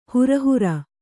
♪ hura hura